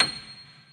piano-sounds-dev
Vintage_Upright
c7.mp3